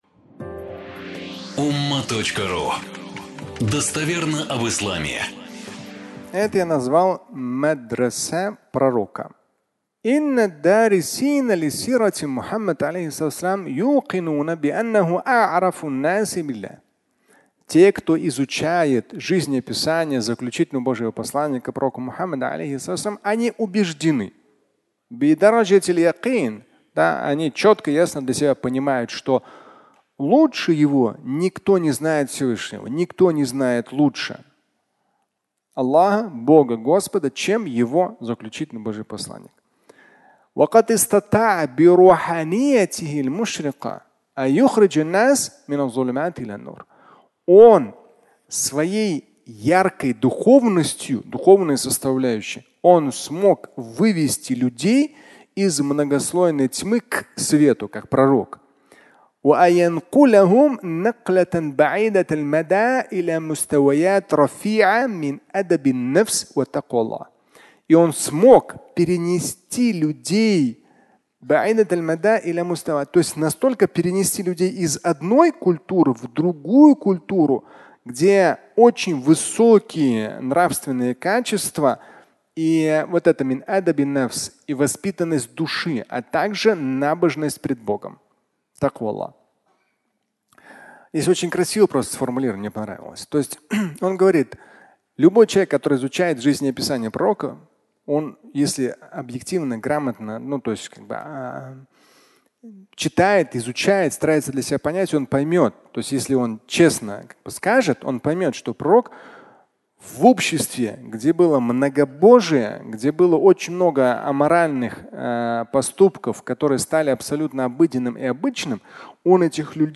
Медресе Пророка (аудиолекция)